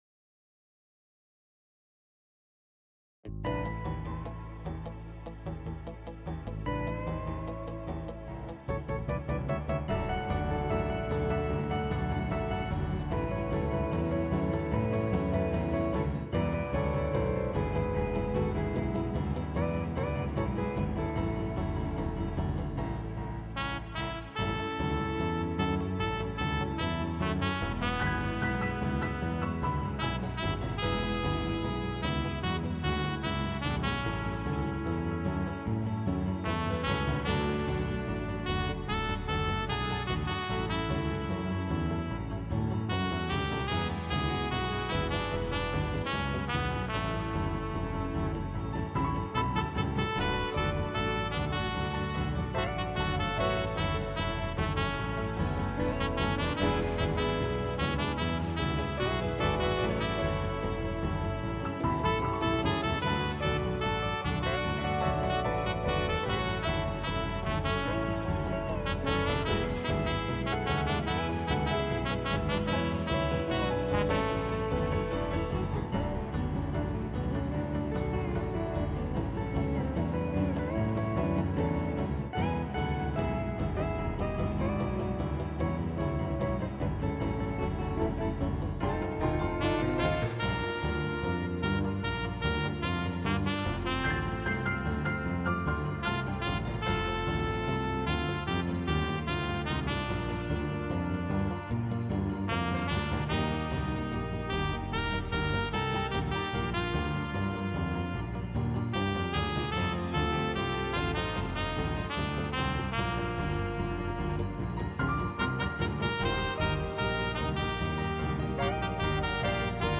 PRO MIDI Karaoke INSTRUMENTAL VERSION